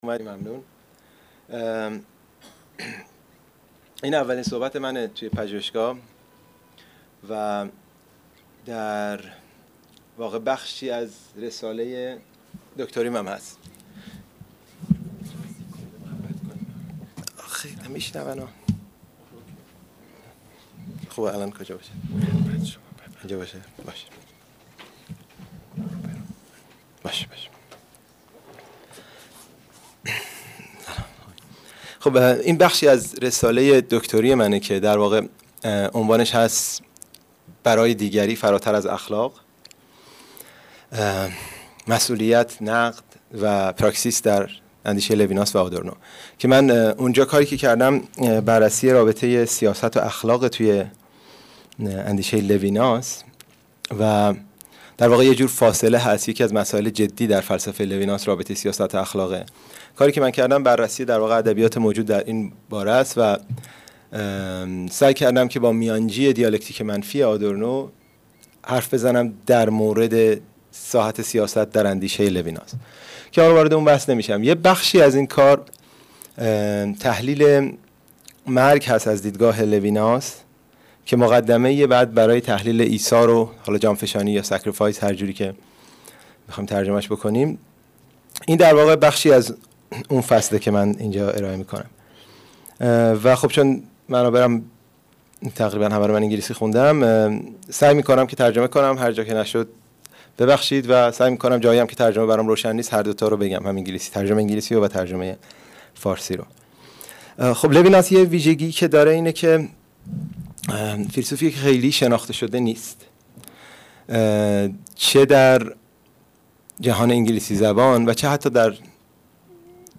گروه دین و فرهنگ غرب معاصر پژوهشکده غرب شناسی و علم پژوهی برگزار می کند: